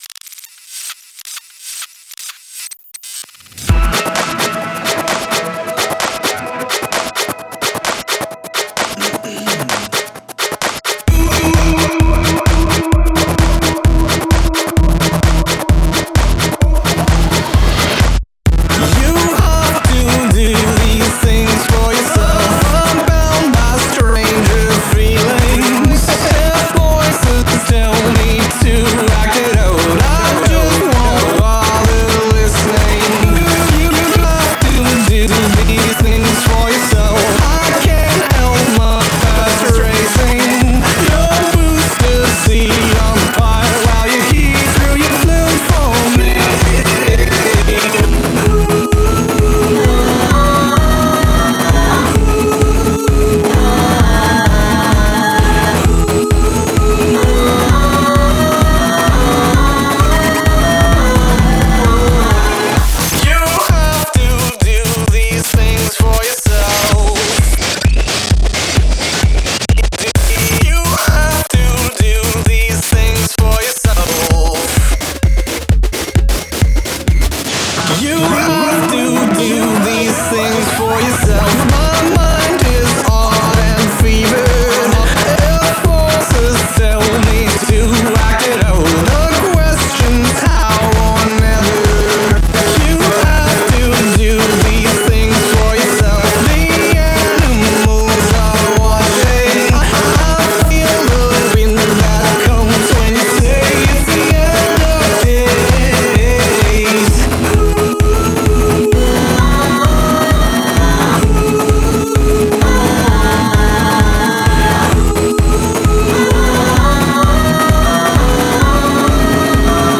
Their music is catchy fun techno dance pop.
Music being fun, catchy, energetic and great to dance to.